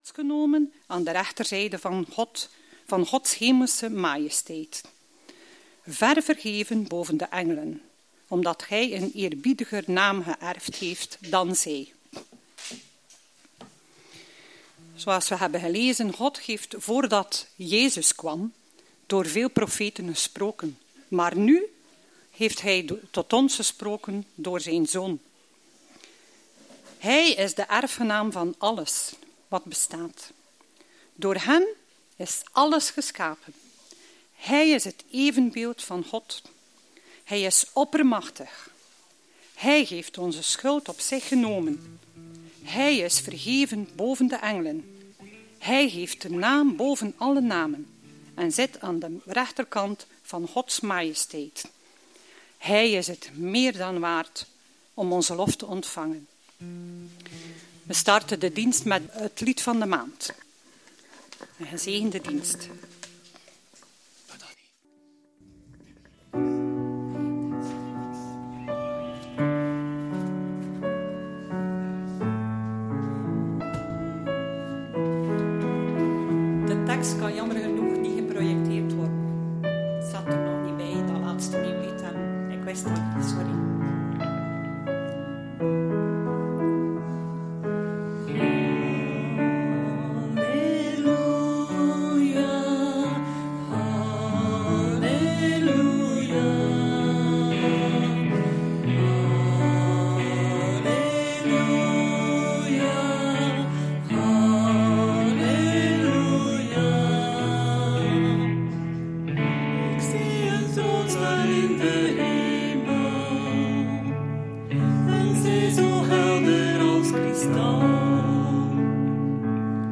Thema van de preek : “Let op !!”